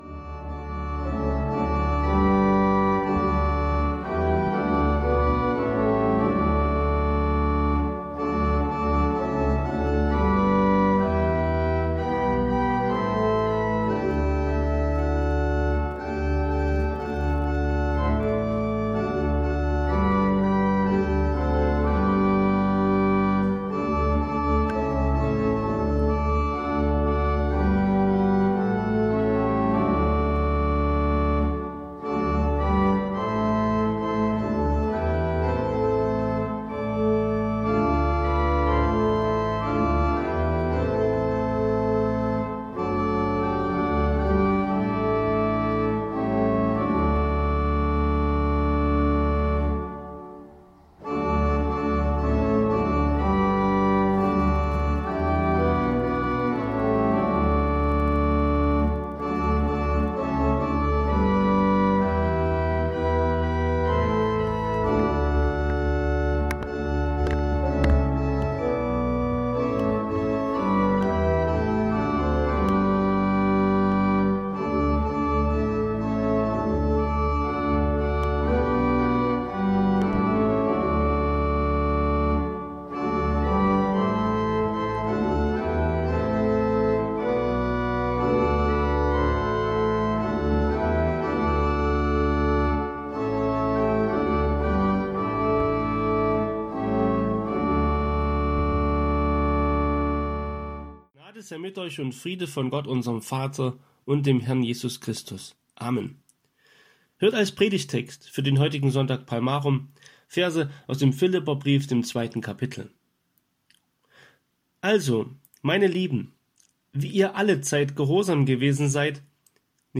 Predigt Palmarum 2020.mp3